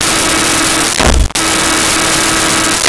Bus Horns 01
Bus Horns 01.wav